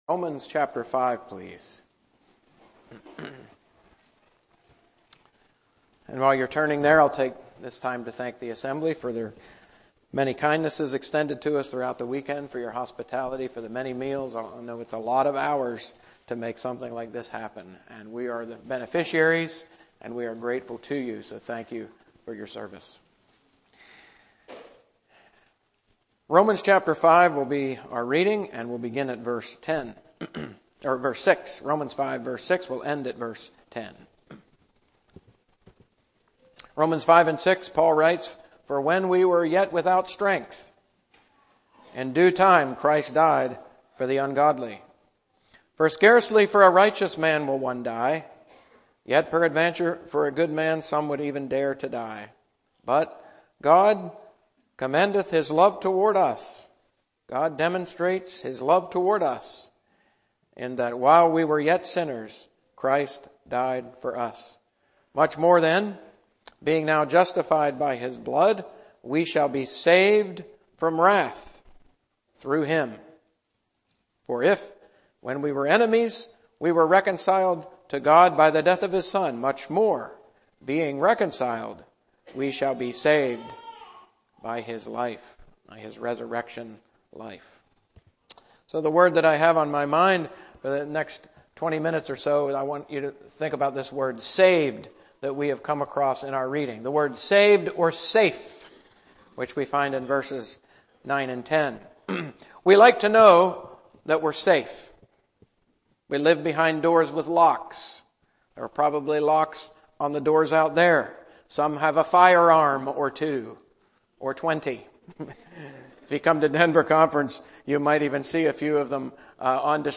Arlington Youth Conference 2026